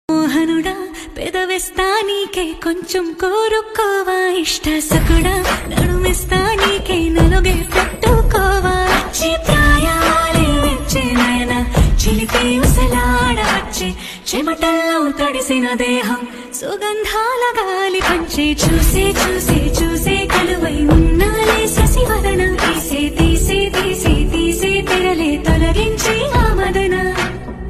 Download this magical Telugu melody for a perfect vibe.